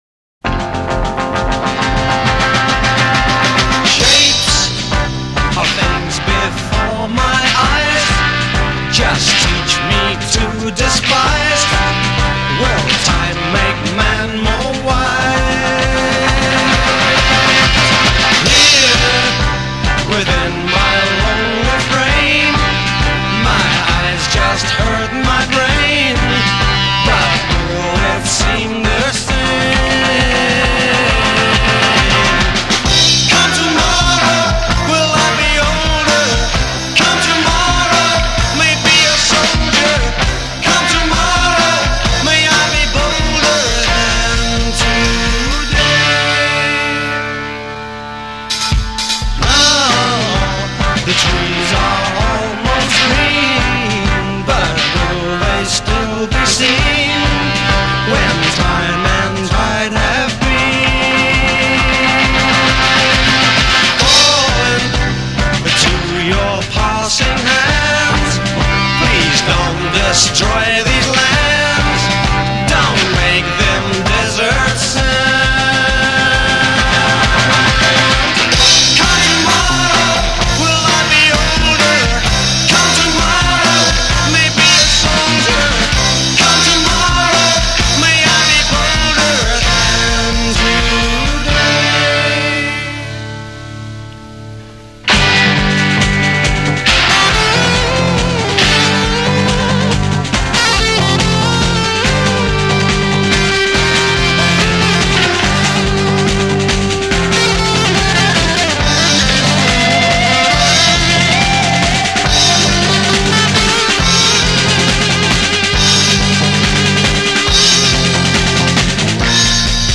Intro 2 Ensemble triplets (12/8).
Chorus 8 + 1 Soloist responding to chorus (duple time) c
solo 16 +1 guitar solo over two-chord vamp & drum roll.
Outro Fade Tremolo on guitar?